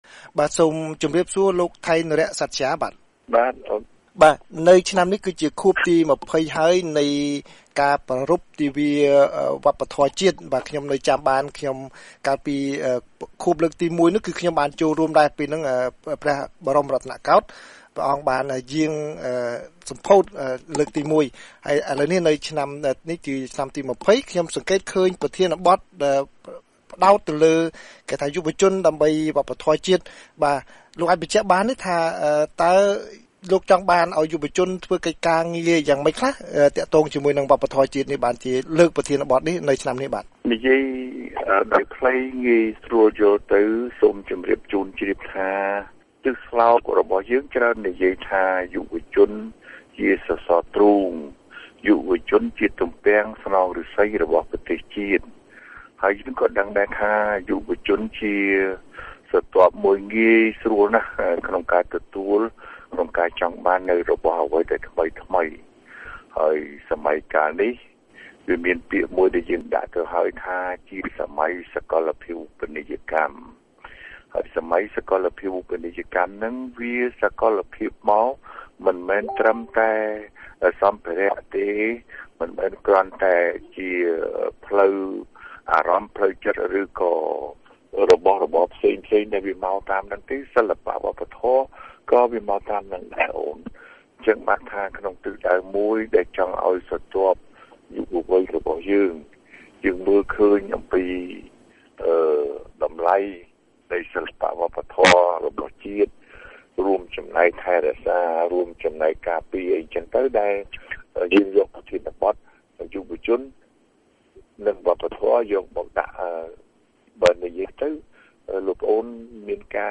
បទសម្ភាសន៍ VOA៖ ទិវាវប្បធម៌ជាតិឆ្នាំទី២០ពង្រឹងការចូលរួមរបស់យុវជន